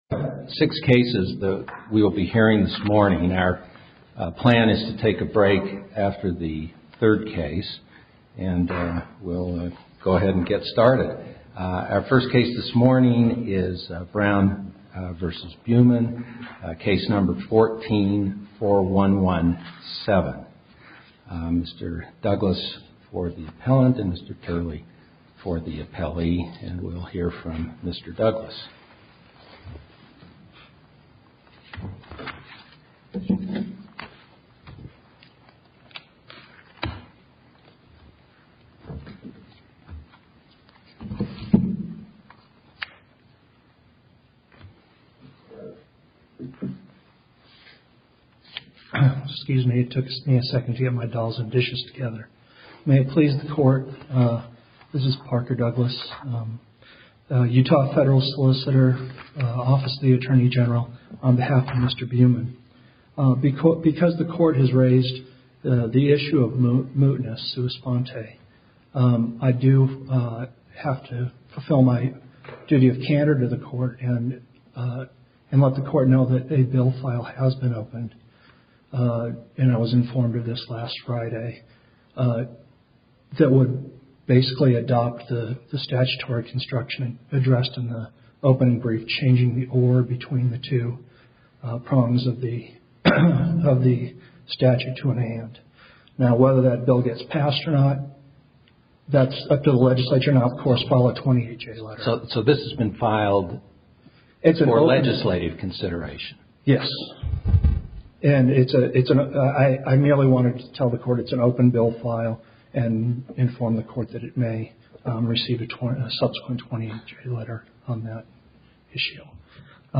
Hear the entirety of the arguments from the 10th Circuit Court in Denver, as Utah officials defended the state's ban on polygamy, and lawyer for a reality TV family asked the court to uphold a ruling that struck parts of that ban down.